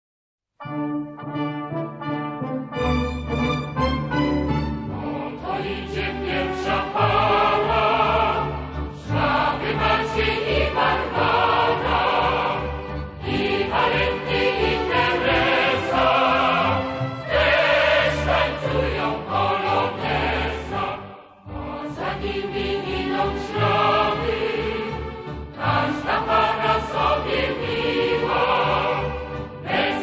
Archival recordings from years 1961-1970